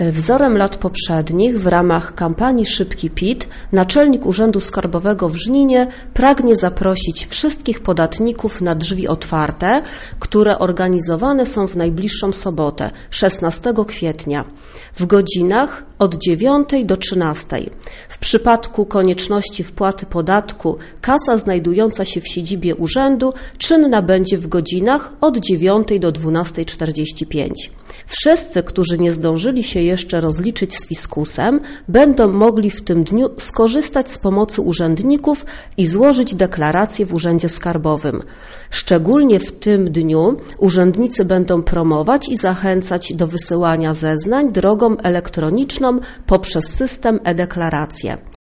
mówi rzecznik